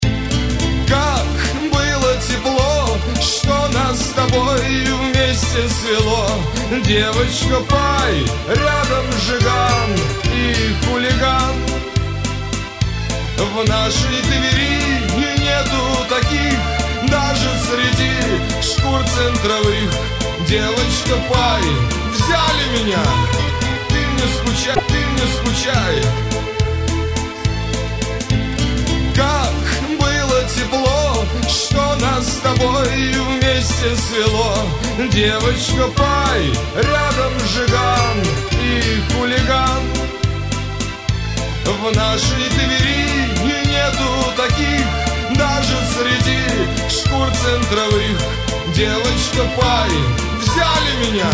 из Шансон
Категория - шансон.